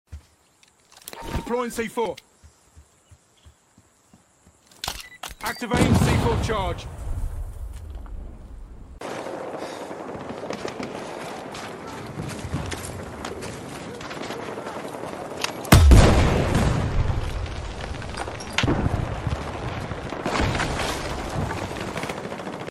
C4 on Palm Tree 🌴 sound effects free download
C4 on Palm Tree 🌴 explosion 💥 comparison